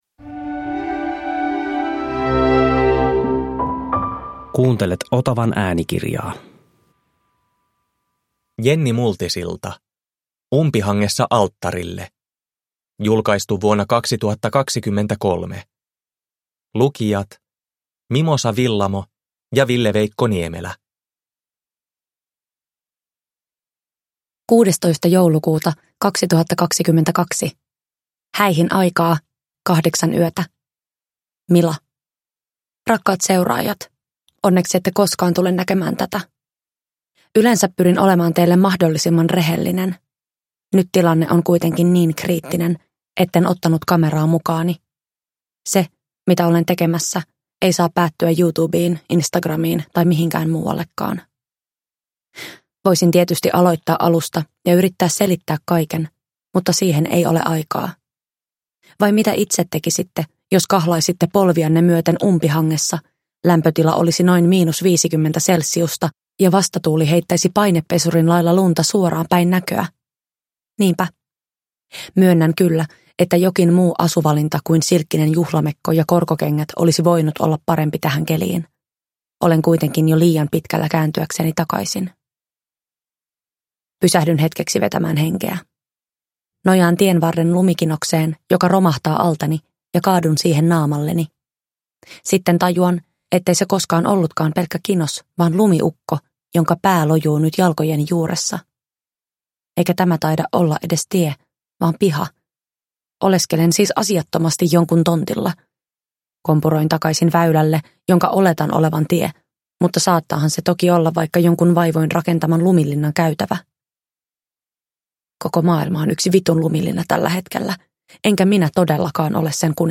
Umpihangessa alttarille – Ljudbok – Laddas ner